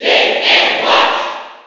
File:Mr. Game & Watch Cheer NTSC SSB4.ogg
Mr._Game_&_Watch_Cheer_NTSC_SSB4.ogg